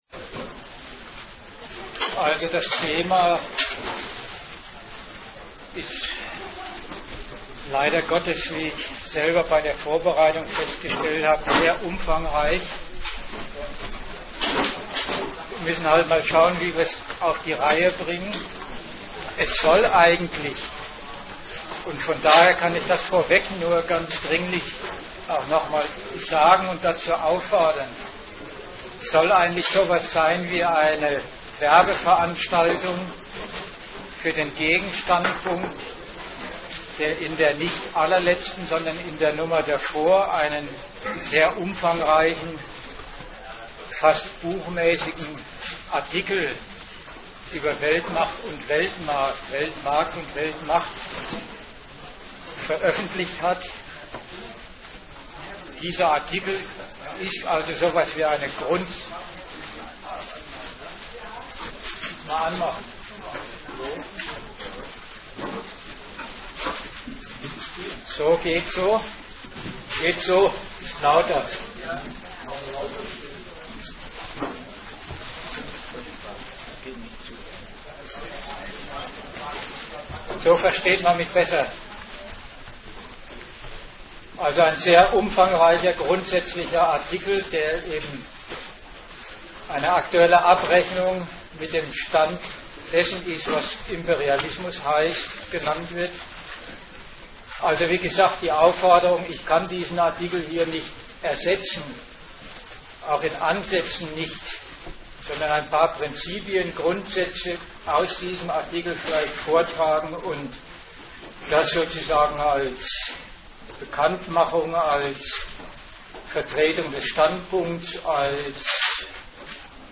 Die Veranstaltung will aufklären über den notwendigen Zusammenhang der Konkurrenz auf dem Weltmarkt und der Konkurrenz ums ‚Weltordnen’. Teil 1-3: Die Konkurrenz des globalisierten Kapitals Teil 4: Der Kampf der Nationen mit und um Geld und Macht Teil 5: Die Rolle der Völker Anmerkung: Zwischen Teil 3 und Teil 4 gibt es nur eine "akustische Lücke".